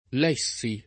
l$ggo], ‑gi — pass. rem. lessi [